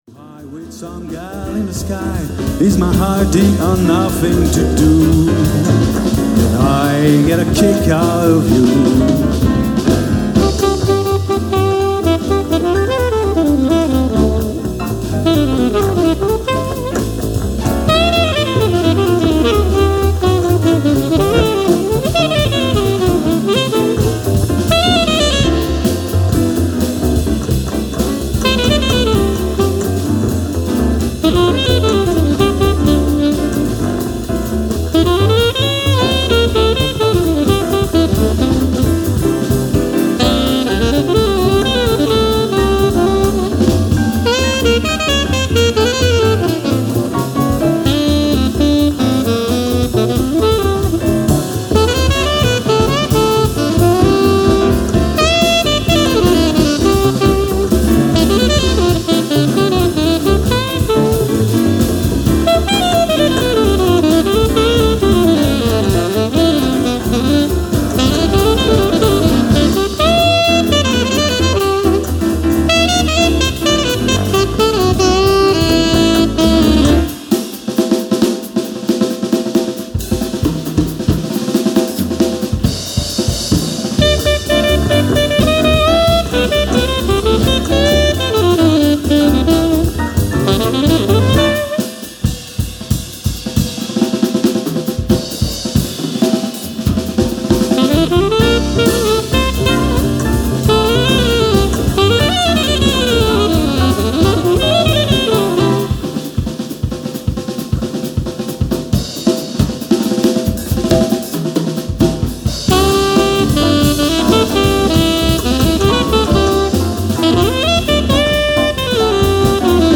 si j'avais voulu plus de Loudness (mais à mon avis pas nécessaire; => ceux qui veulent écouter plus fort n'ont qu'à monter le volume de leur chaine !!!!!) j'aurais modifié les réglages de mastering pour obtenir ce genre de résultat :
jazz_loud (et il en reste encore plein de marge pour faire du genre FUN-RADIO si on le souhaitait...)
jazz_loud.mp3